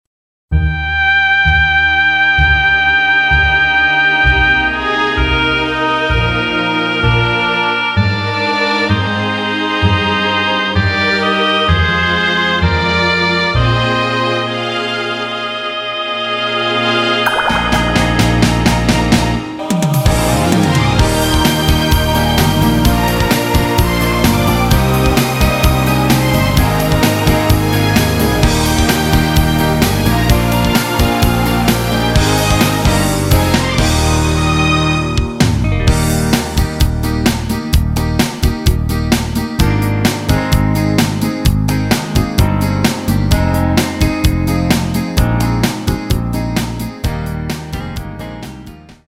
MR 입니다.